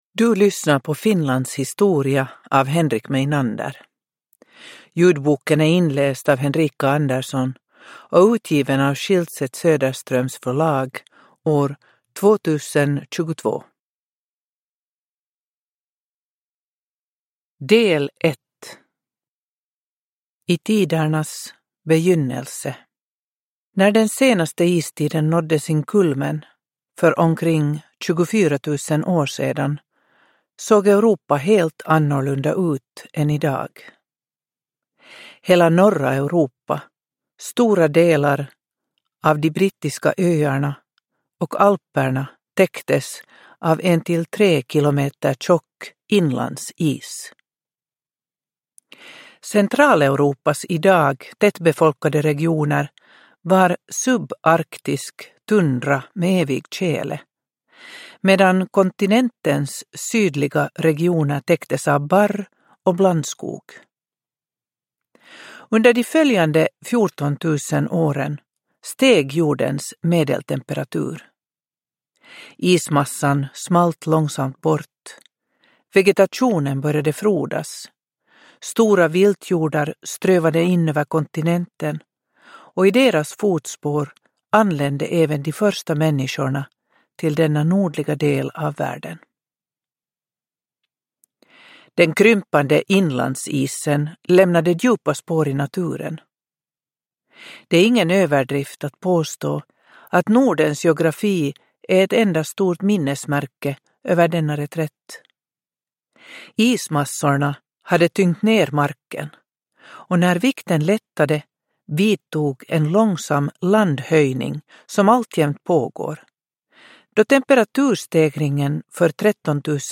Finlands historia – Ljudbok – Laddas ner